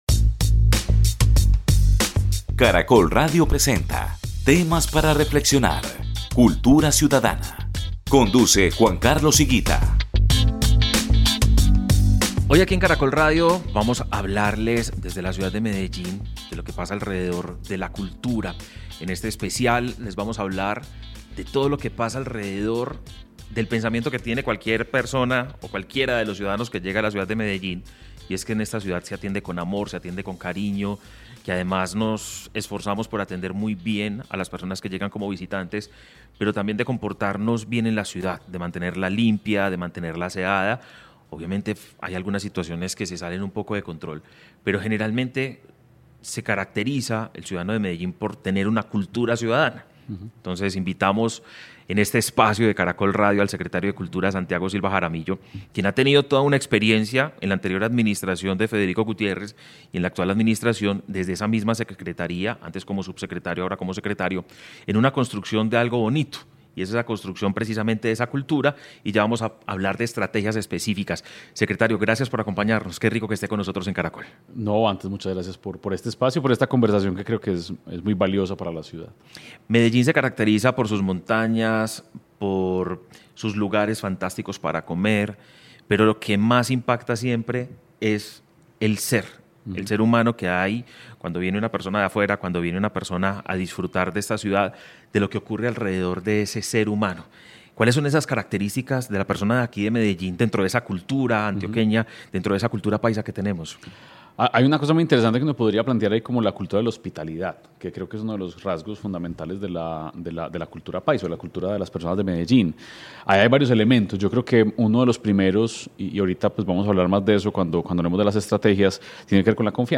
Una conversación